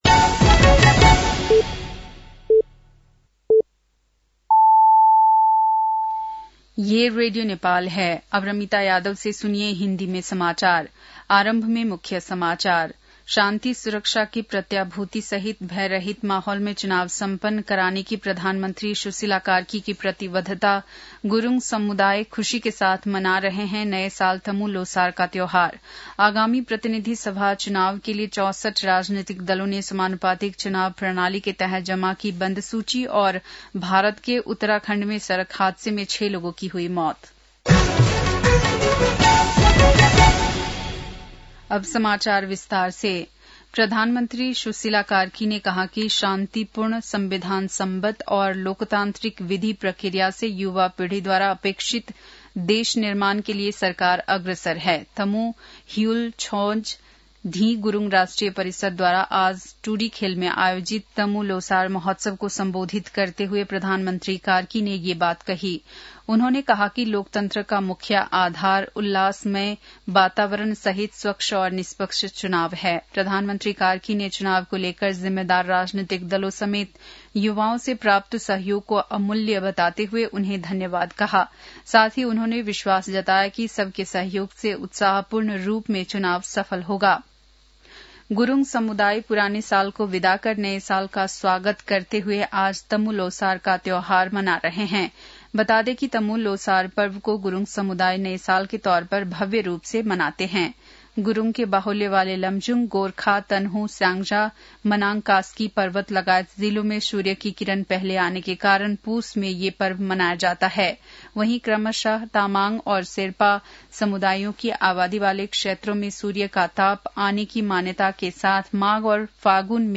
बेलुकी १० बजेको हिन्दी समाचार : १५ पुष , २०८२
10-pm-hindi-news-9-15.mp3